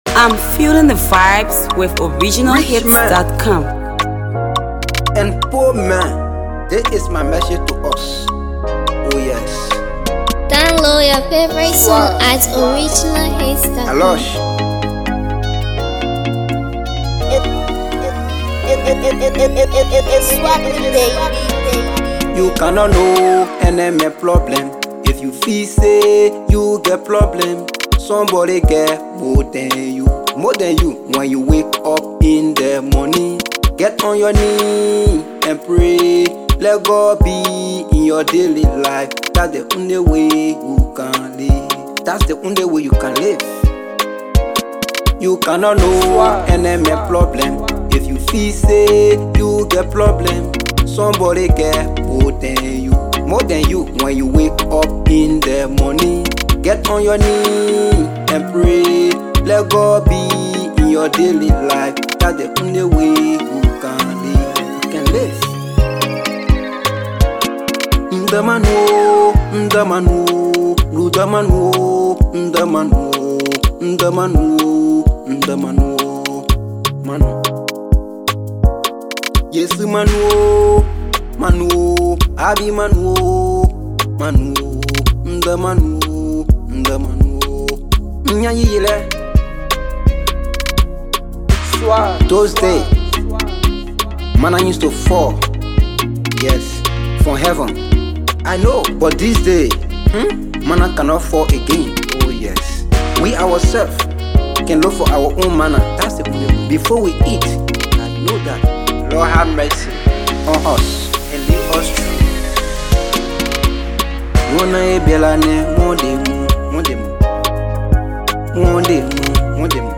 AfroLATEST PLAYLISTMusic